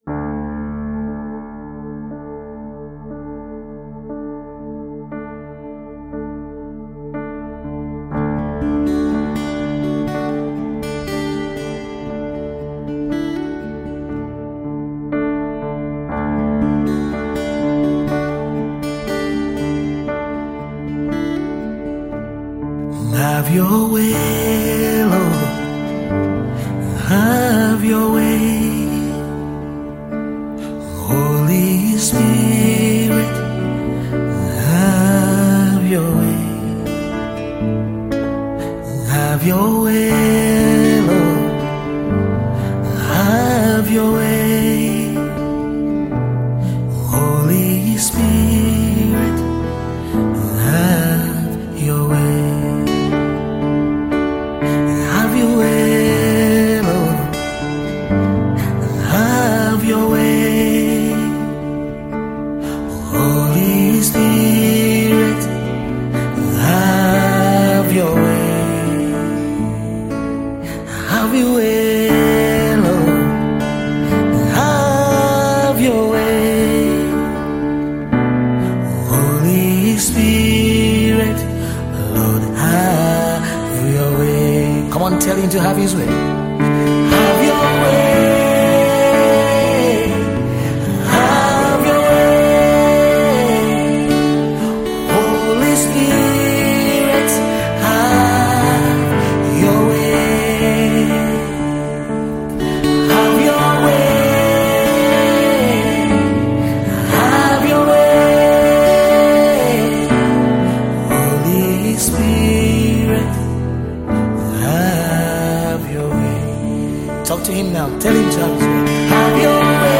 pure worship song of consecration to God